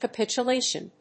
ca・pit・u・la・tion /kəpìtʃʊléɪʃən/
• / kəpìtʃʊléɪʃən(米国英語)